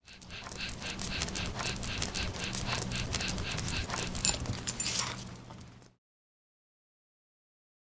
dog soundeffect sound effect free sound royalty free Sound Effects